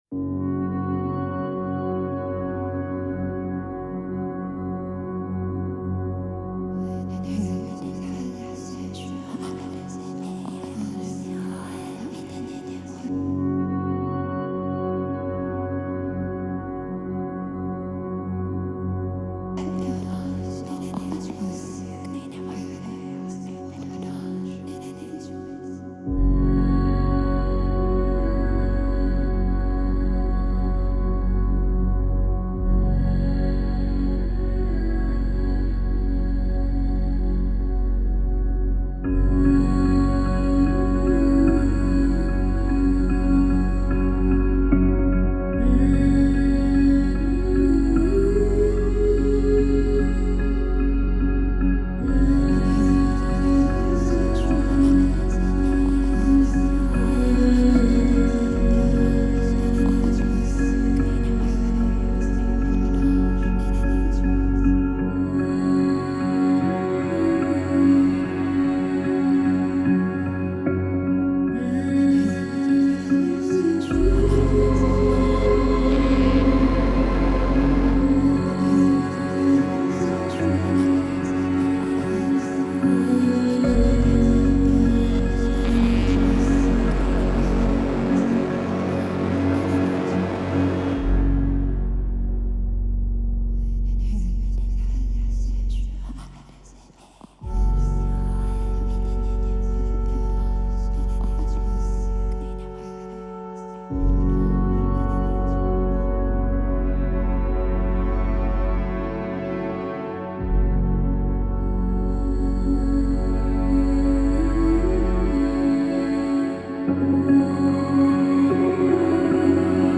Melancholic female vocal textures and subtle piano.